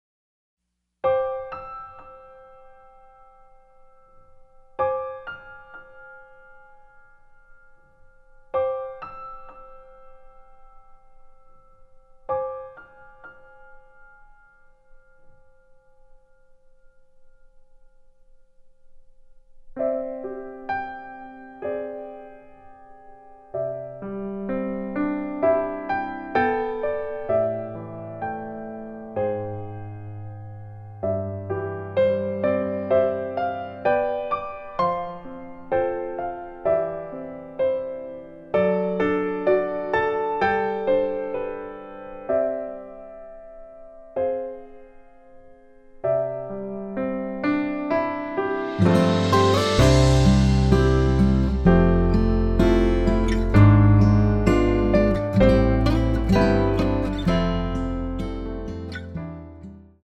공식 음원 MR
앞부분30초, 뒷부분30초씩 편집해서 올려 드리고 있습니다.
중간에 음이 끈어지고 다시 나오는 이유는